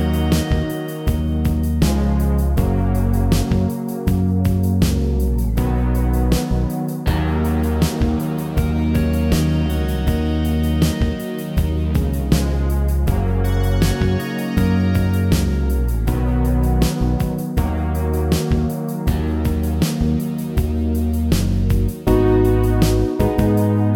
no Backing Vocals Soft Rock 3:01 Buy £1.50